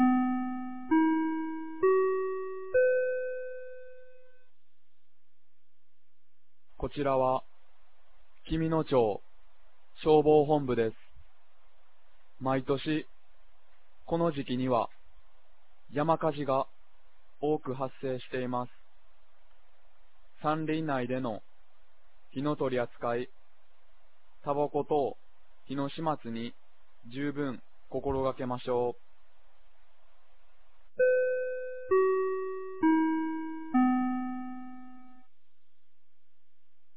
2025年05月10日 16時00分に、紀美野町より全地区へ放送がありました。